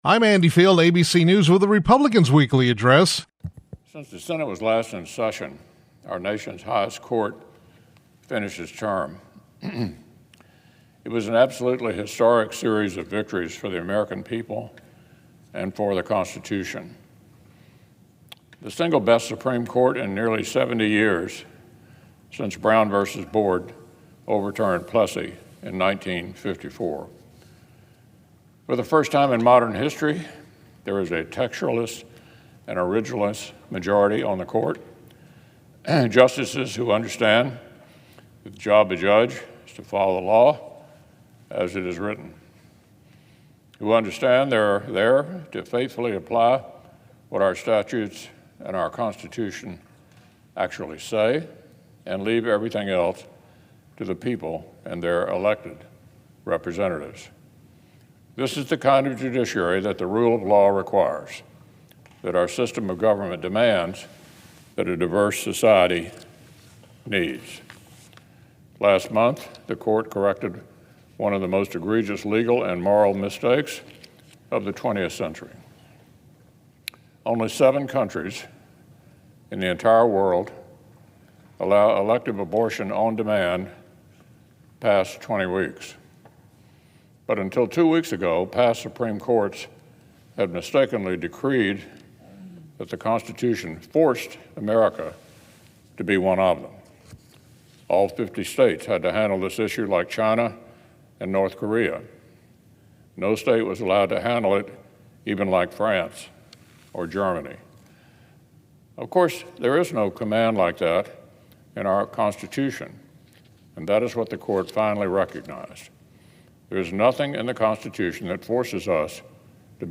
U.S. Senate Republican Leader Mitch McConnell (R-KY) delivered the remarks on the Senate floor regarding the Supreme Court.